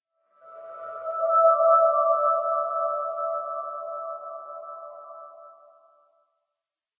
cave2.ogg